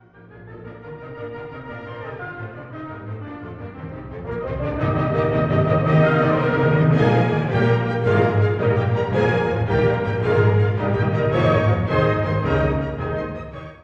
↑古い録音のため聴きづらいかもしれません！（以下同様）
Scherzo: Allegro vivace
～スケルツォ：快活に速く～
とても軽やか。
3拍子のスケルツォと、優雅なトリオが交互にあらわれる楽章です。